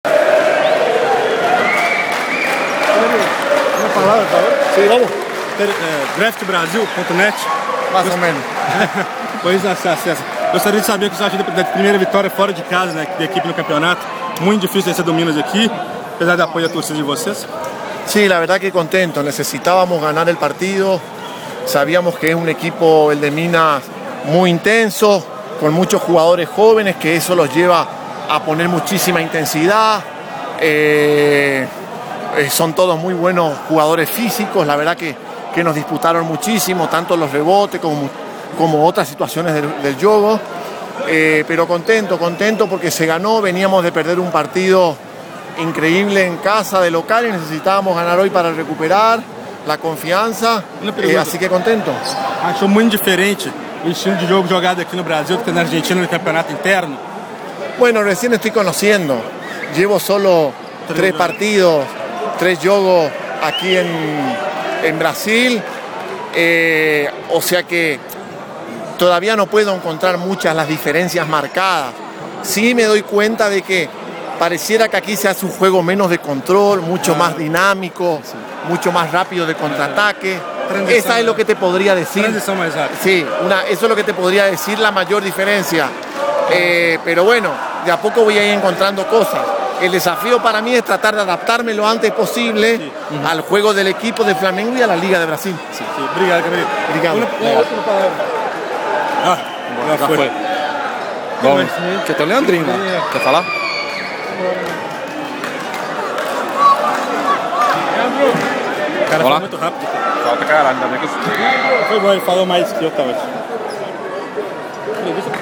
Entrevistas: